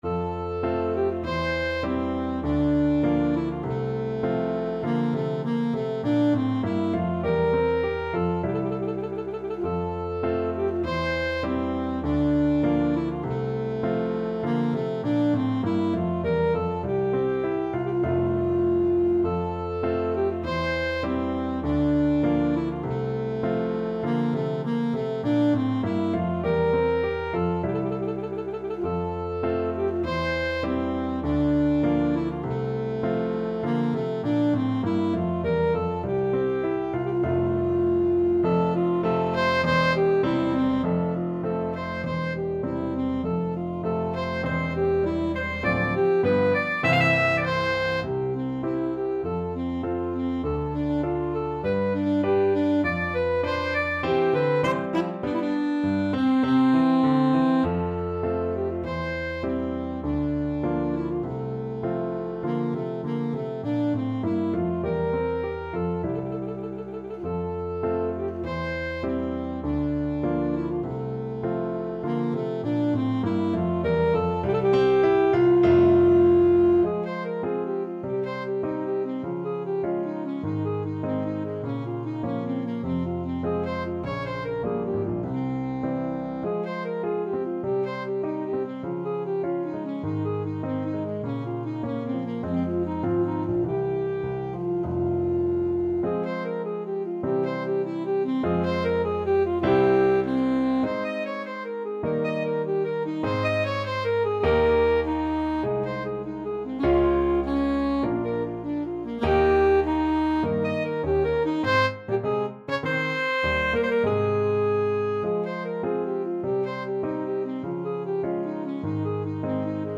Alto Saxophone
F major (Sounding Pitch) D major (Alto Saxophone in Eb) (View more F major Music for Saxophone )
2/2 (View more 2/2 Music)
~ = 100 Allegretto
Classical (View more Classical Saxophone Music)